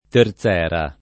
terzera
[ ter Z$ ra ]